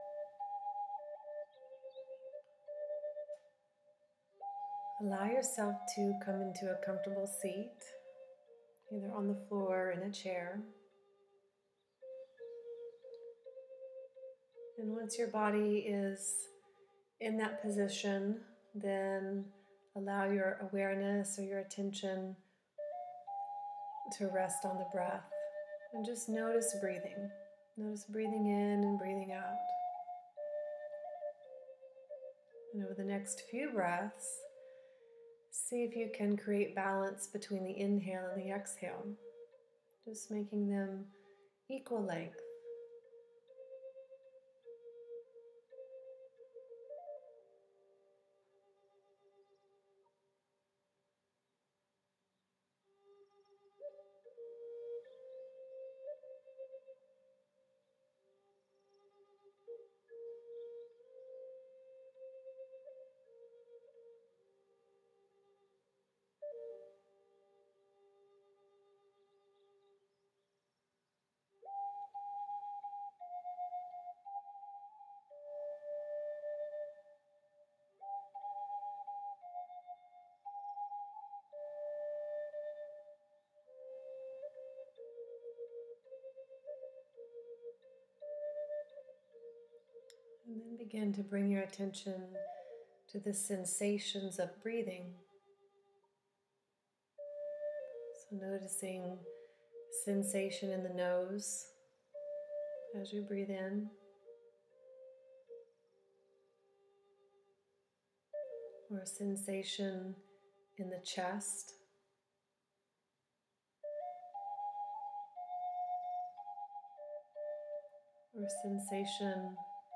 Calming and Energizing Breath Meditation
Calming_Energizing-Breath-Meditation.m4a